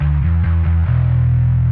描述：电贝司
Tag: 贝司